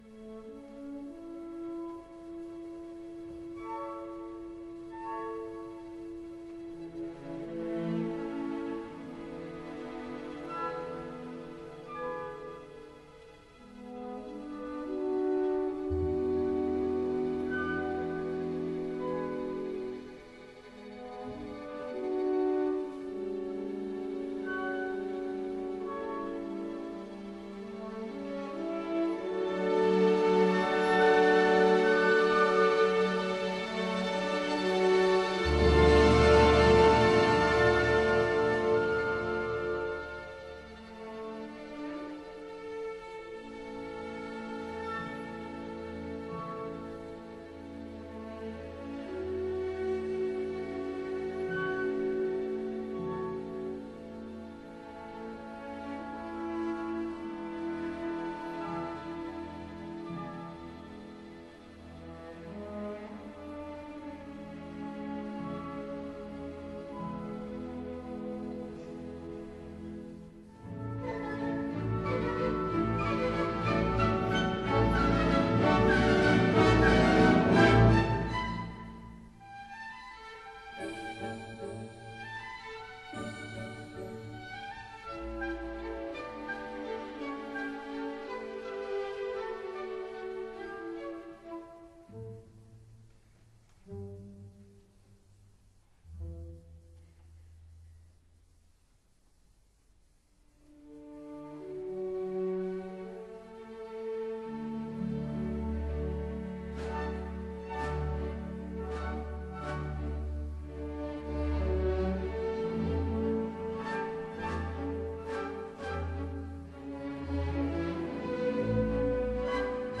Vienna - Organ Grinder